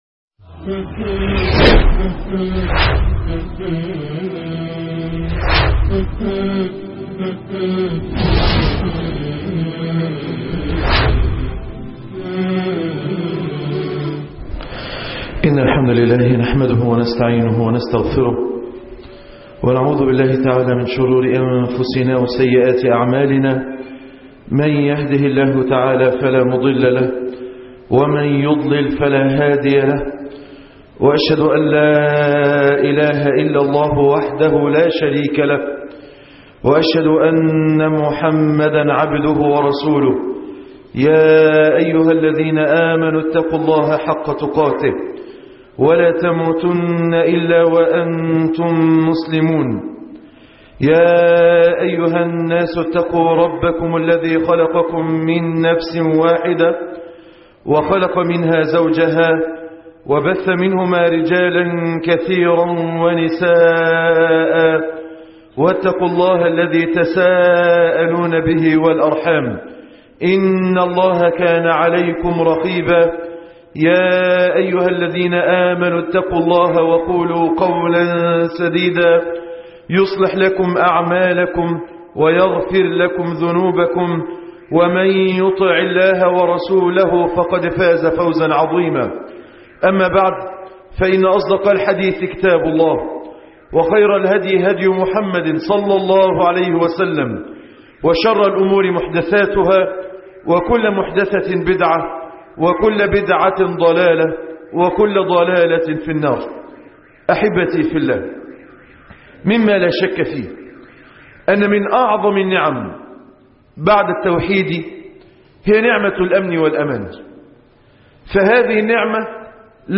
خطب الجمعه